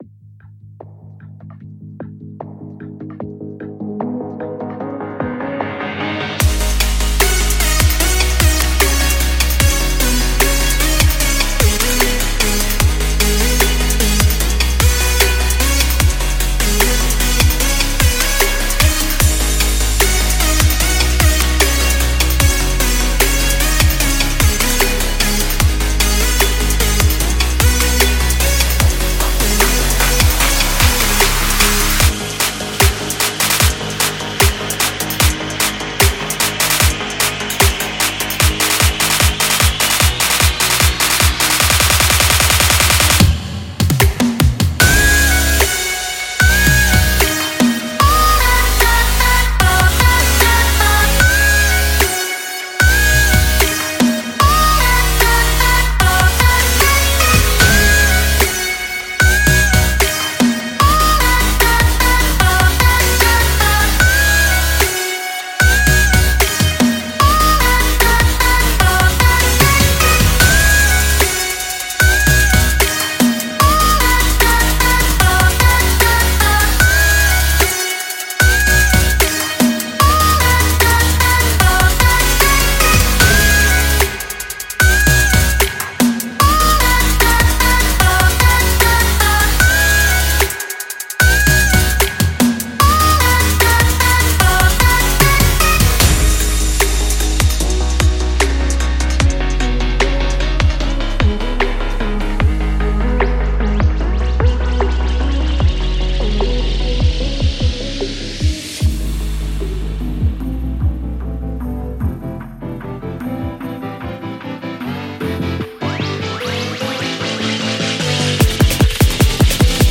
43个FX样本  –通过这43个独特的FX样本，像专业人士一样建立和释放张力
211个合成器循环和115个MIDI循环  –超过200个吸引人的合成器循环，使您的音乐更令人难忘
147个鼓点和146个鼓环  –鼓准备用于将来的低音和陷阱，使找到合适的样本变得容易
30个声乐印章循环  –适用于所有未来低音和陷阱场合的动人的声乐印章旋律！